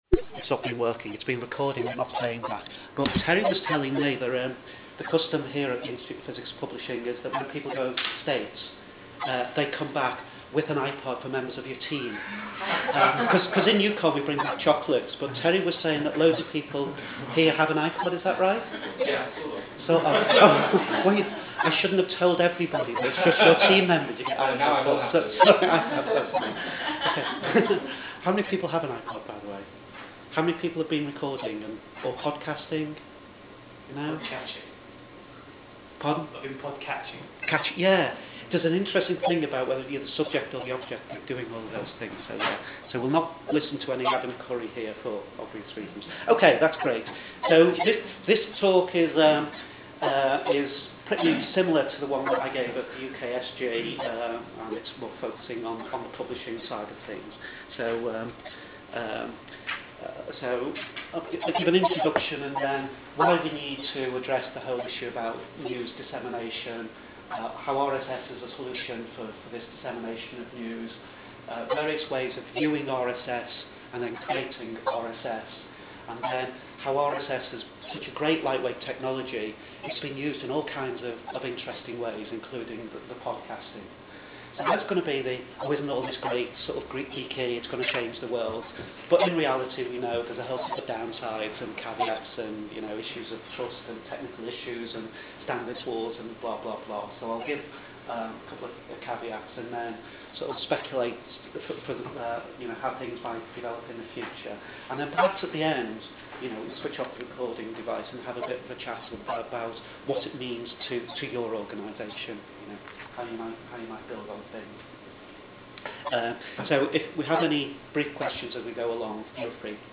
The seminar was held in the Conference Room, Institute of Physics Publishing, Dirac House, Temple Back, Bristol BS1 6BE.
It was taken used a Belkin Voice recorder and an iPod. The iTunes software was then used to convert the WAV file to MP3.
Recording [ MP3 format, 39 Mb, 1 hour ] Podcast [ RSS format ] - [ Validate Feed ] Note that the quality of the recording is not very good for a number of reasons: When I gave the talk I moved away from the microphone. The quality of the recording was limited by the quality of the microphone and the tecnhical limitations of the iPod software (e.g. mono recording only) The conversion from WAV to MP3 appears to have introduced some audio flaws.